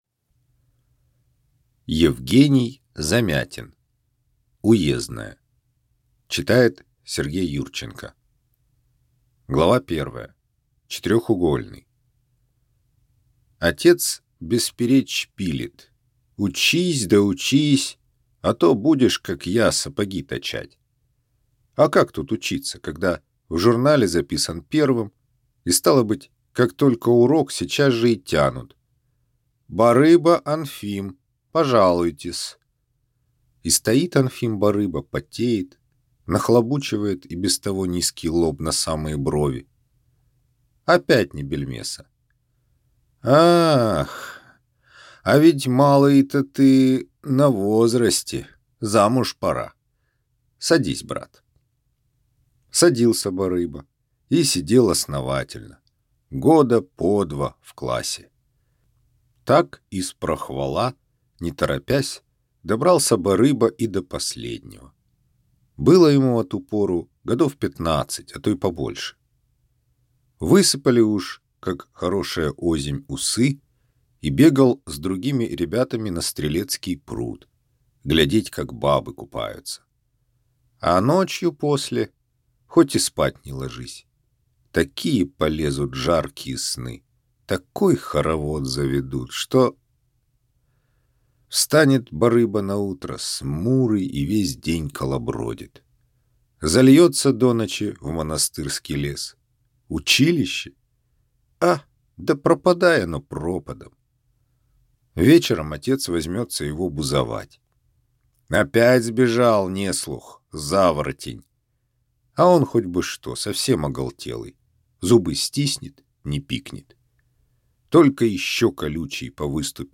Аудиокнига Уездное | Библиотека аудиокниг